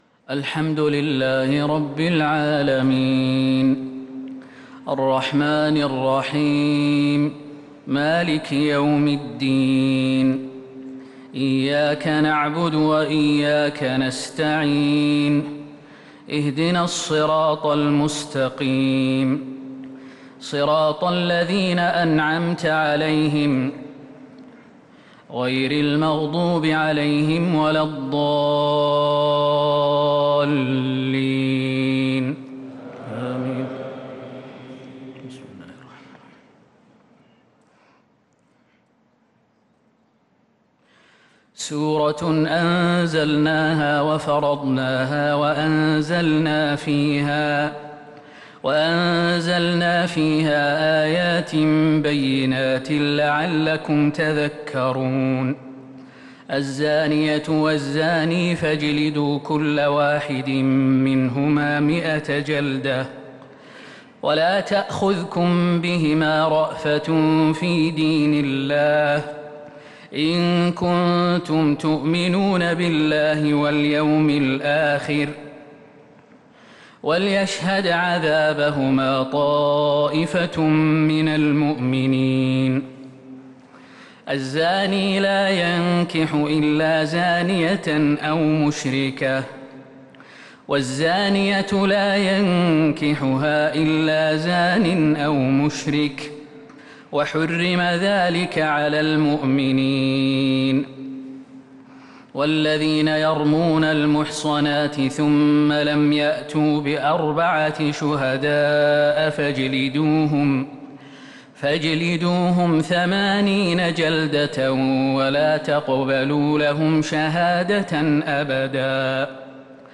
صلاة التهجّد ليلة 22 رمضان 1443هـ سورة النور كاملة | Tahajjud prayer The night of Ramadan 22 1443H from Surah An-Nour > تراويح الحرم النبوي عام 1443 🕌 > التراويح - تلاوات الحرمين